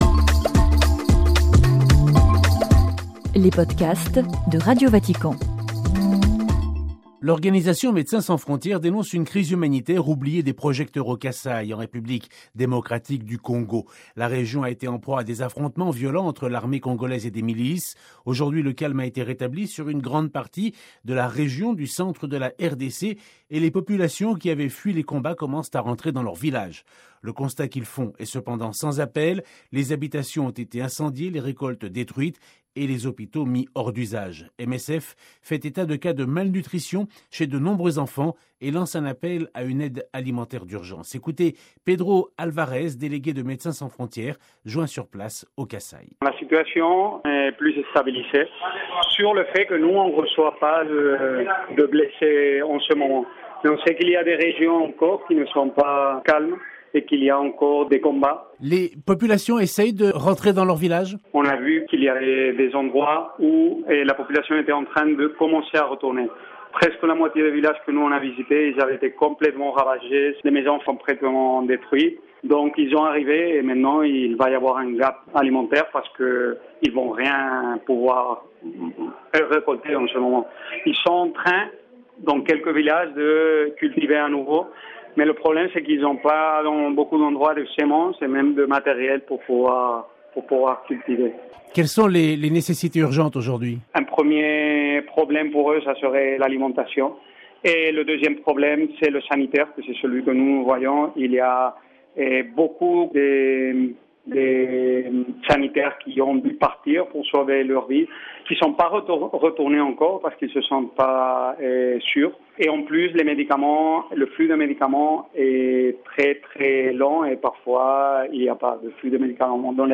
(RV) Entretien - L’organisation Médecins Sans Frontières dénonce une crise humanitaire oubliée de la communauté internationale au Kasai, en République Démocratique du Congo.